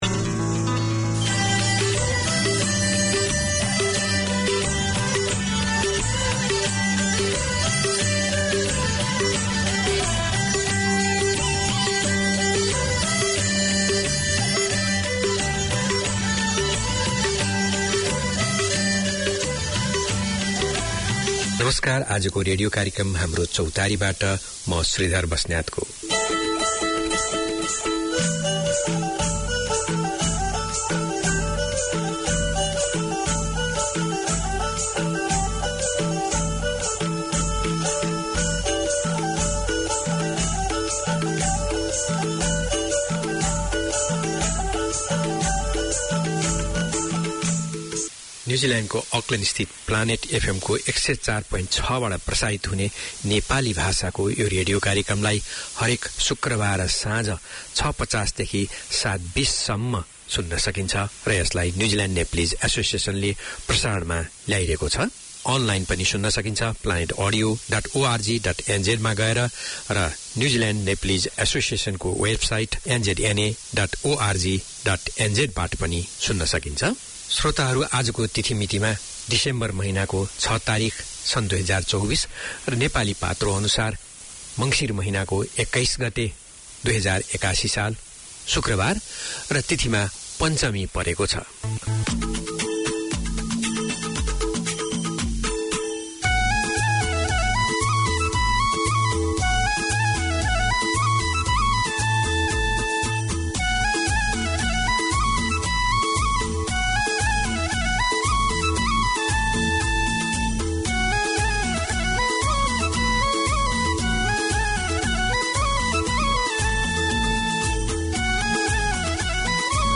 Each week Haamro Chautari offers the chance for the Nepalese community to gather round and share their culture, news of the local community as well as the latest from Nepal. The hosts also present a selection of new music and golden hits. Interviews and updates on community events in Auckland keep the connection with the Nepalese way of life.